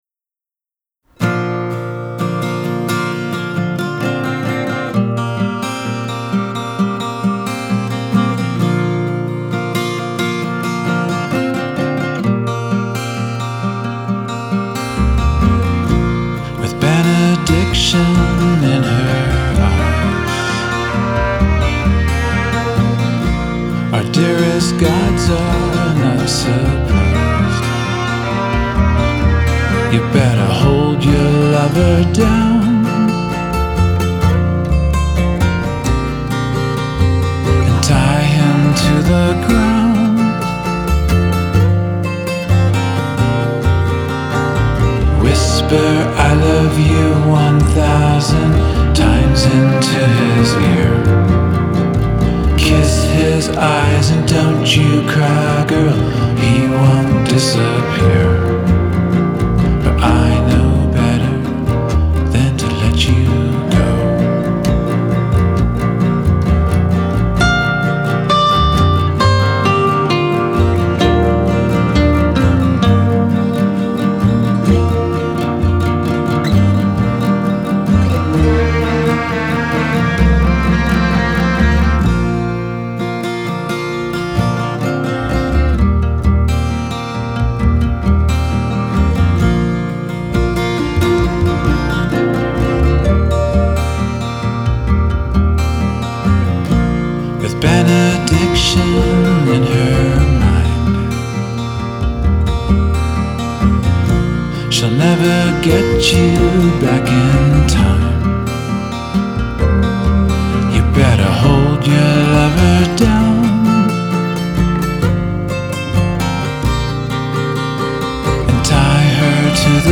Sonic folk music
acoustic guitar
violin